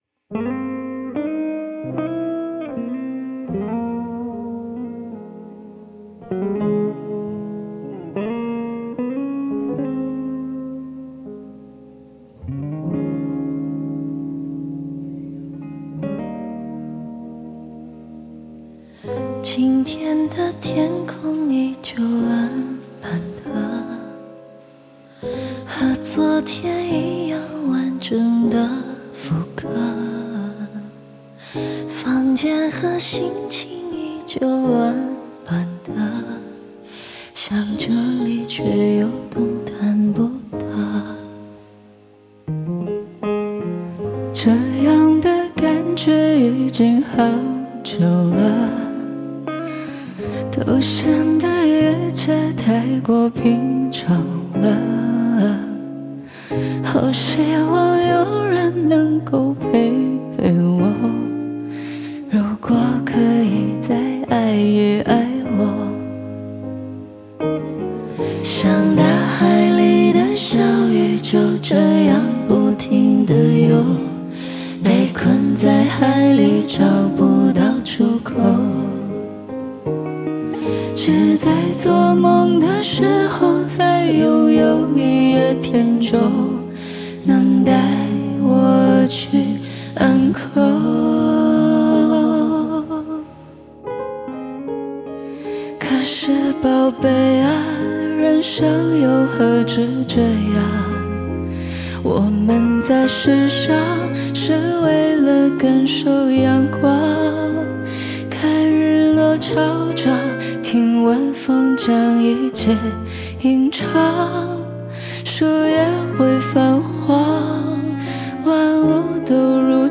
鼓
打击乐
贝斯
吉他
钢琴
键盘